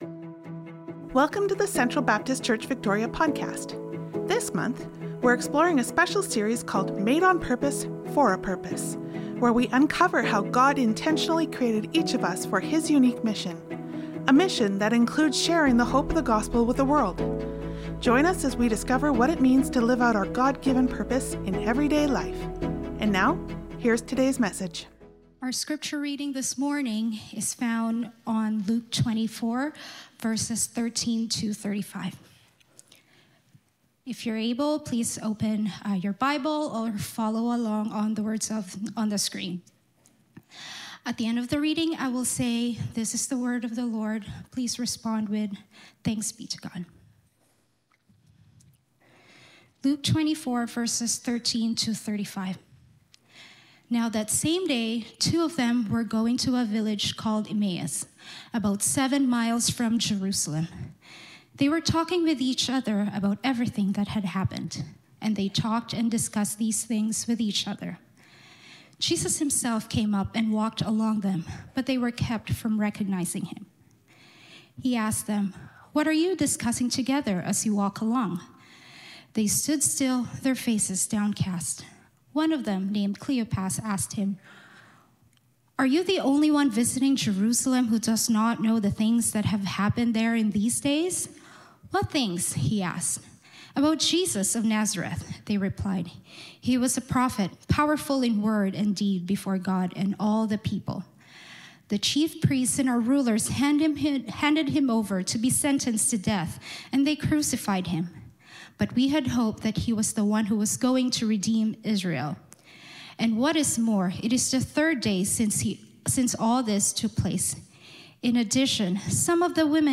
November 23, 2025 Guest Speaker Download Download Reference Ephesians 2:8-10; Romans 10:14-15 Sermon Notes Nov 23'25.Worship Folder.pdf Nov 23'25.Sermon Notes.